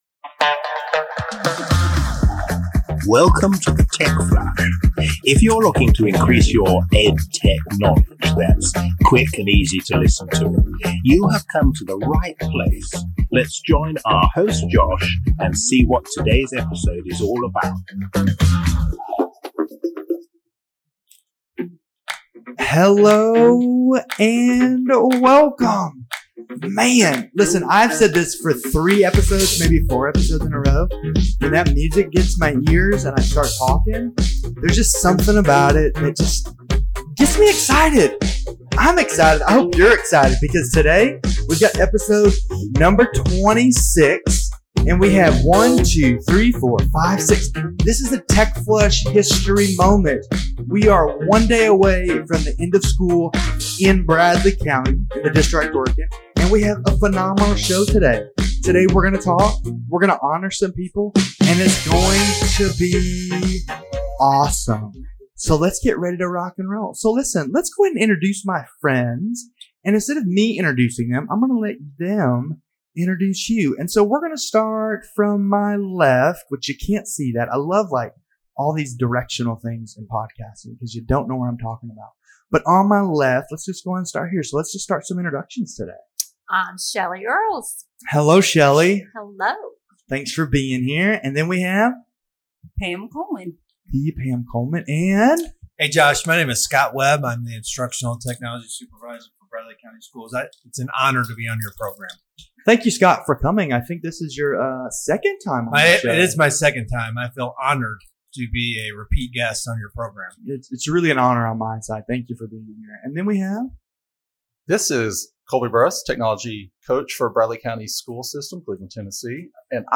Join the Instructional Tech Crew as we wrap up another great school year as we banter back and forth. We also will say goodbye to some and hello to new ones.